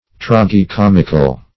Tragi-comic \Trag`i-com"ic\, Tragi-comical \Trag`i-com"ic*al\,